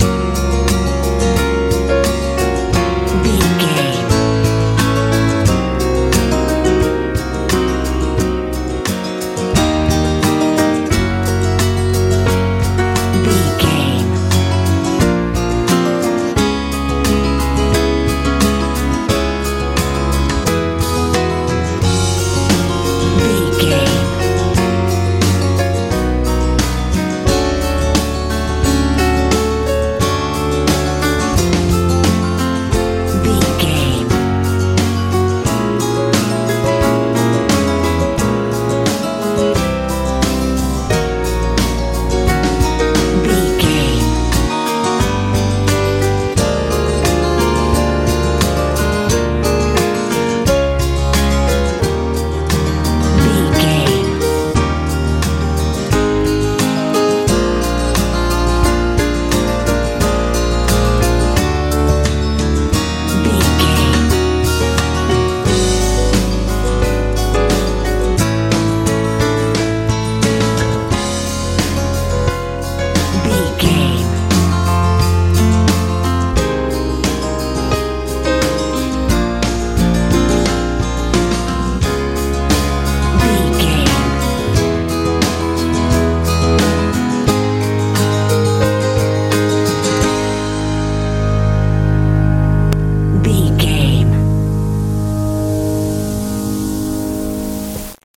pop ballad
Aeolian/Minor
wholesome
acoustic guitar
bass guitar
drums
sweet
smooth
sentimental